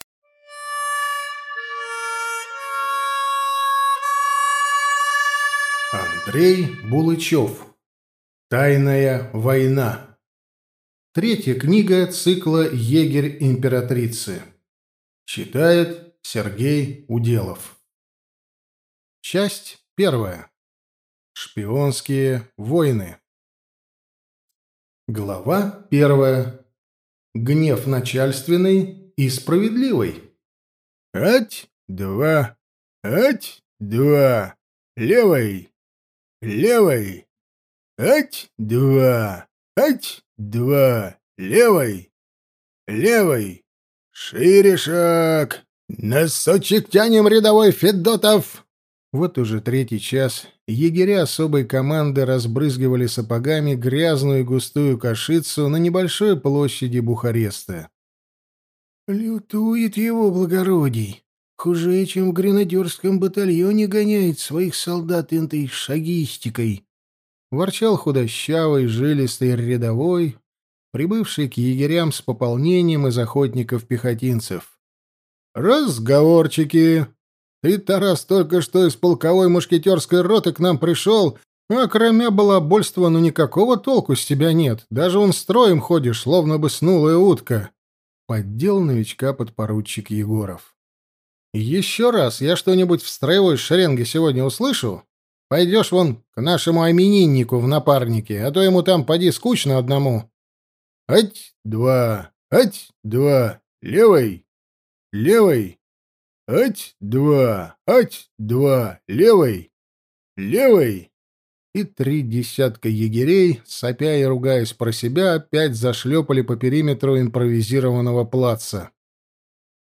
Аудиокнига Тайная война
Качество озвучивания весьма высокое.